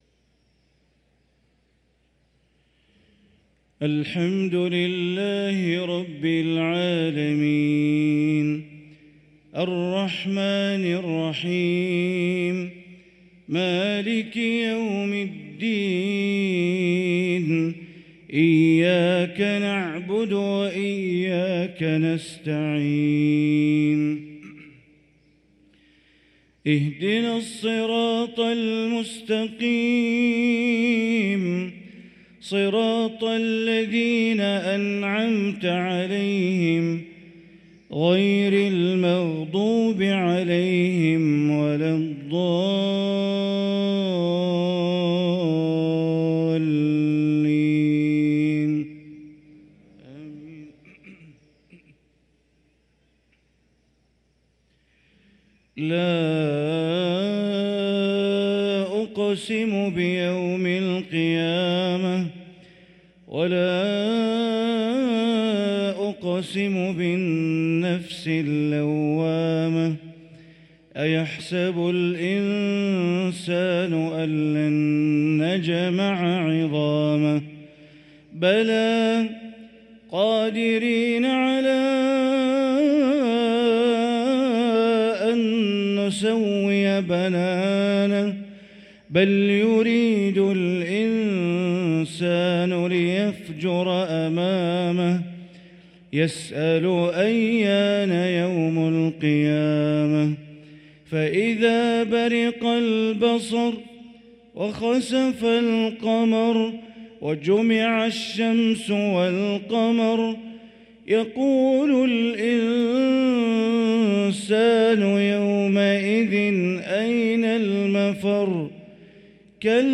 صلاة العشاء للقارئ بندر بليلة 27 جمادي الآخر 1445 هـ
تِلَاوَات الْحَرَمَيْن .